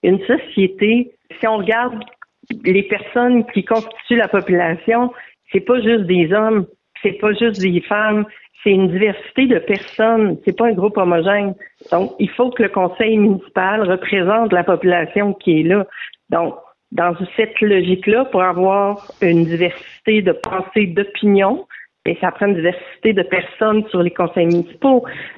En entrevue